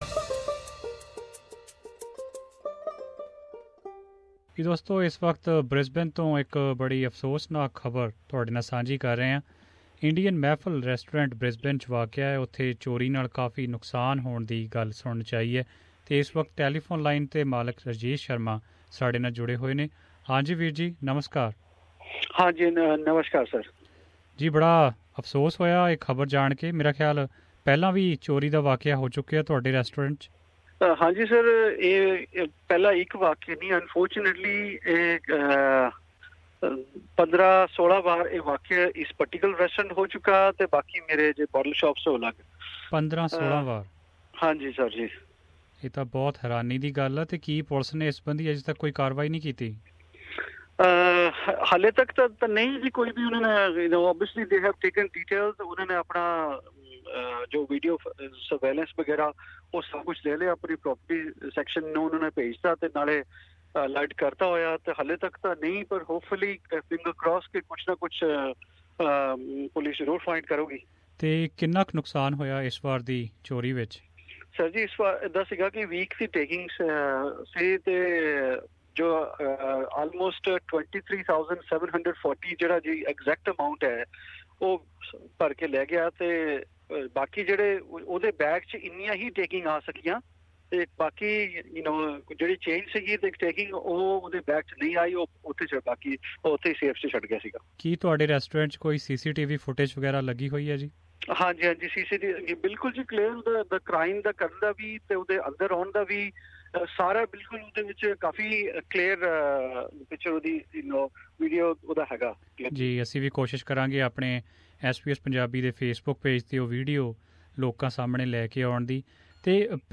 (In English)